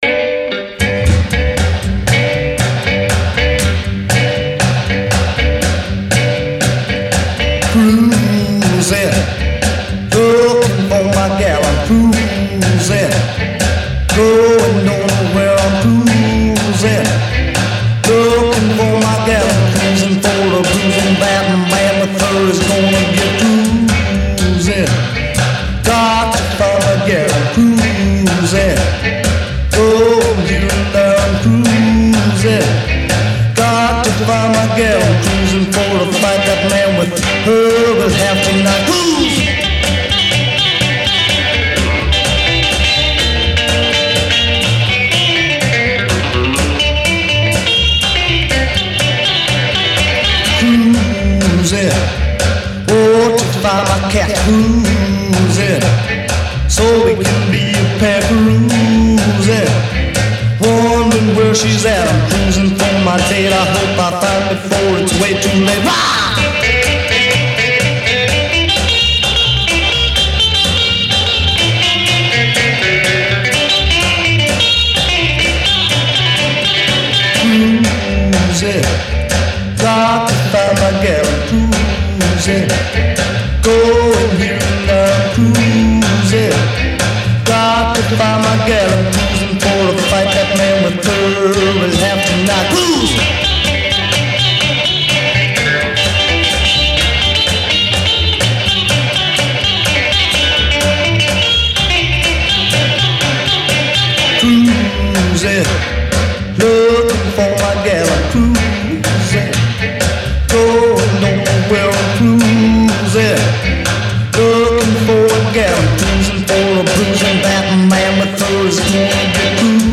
75 cuts of unadulterated Rockabilly